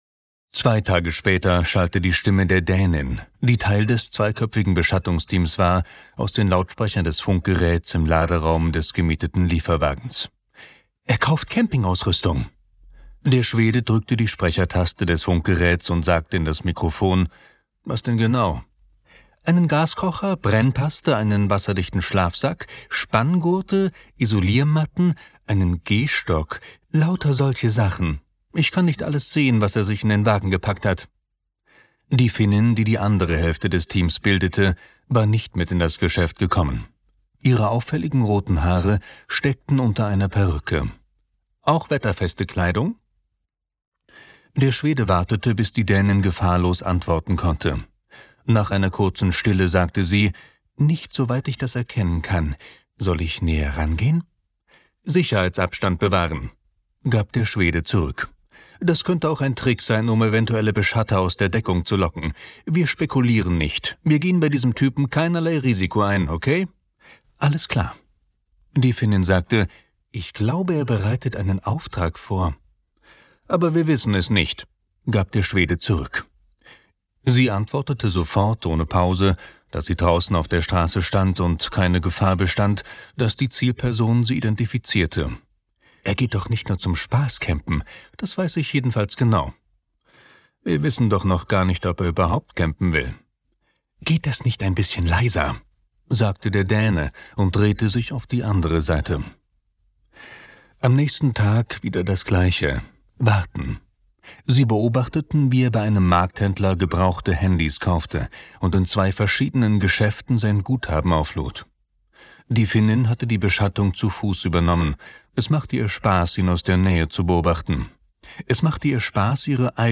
Hörbuch; Krimis/Thriller-Lesung